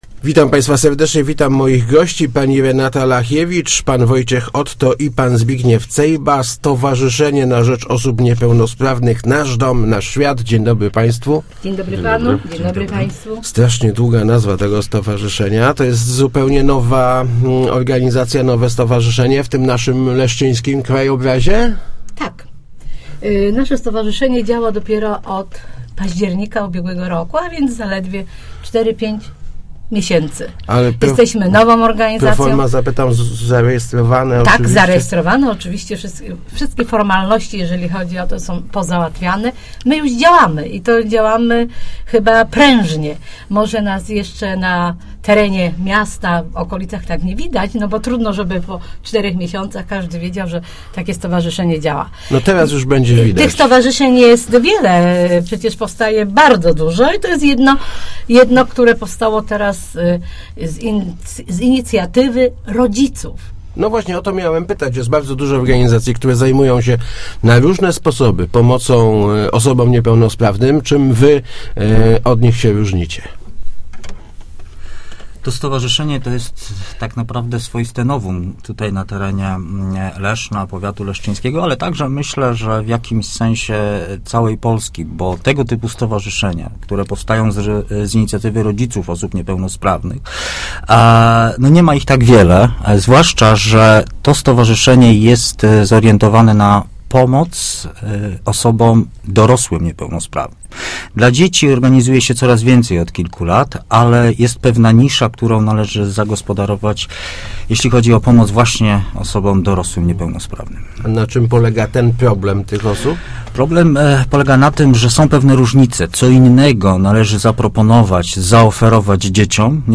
Nasze stowarzyszenie zajmuje si� przede wszystkim dzie�mi niepe�nosprawnymi wchodz�cymi w doros�o�� - mówili w Rozmowach Elki